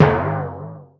BLOOP.WAV